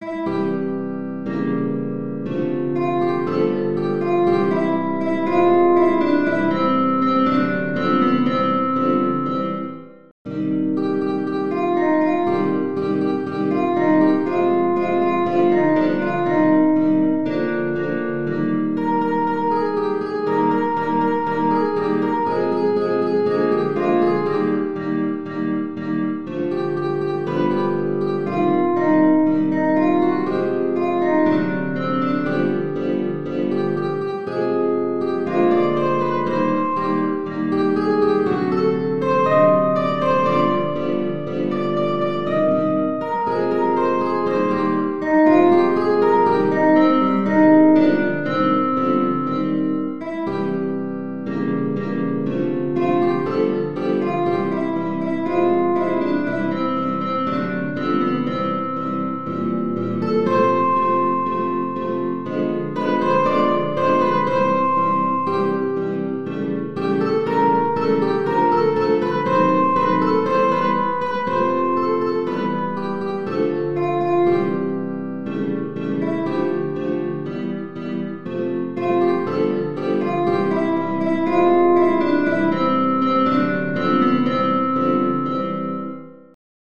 Genere: Ballabili